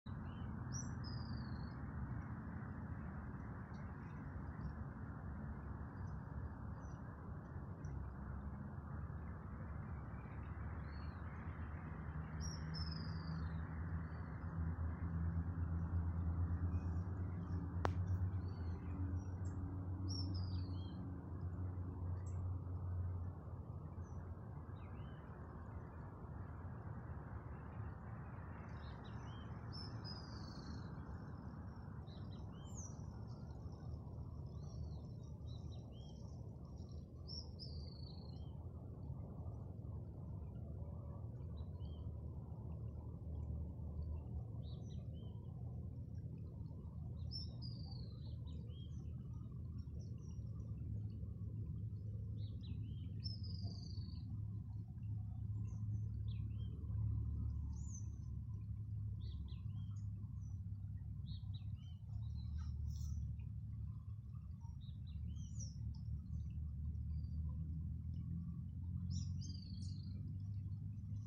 Straneck´s Tyrannulet (Serpophaga griseicapilla)
Condition: Wild
Certainty: Recorded vocal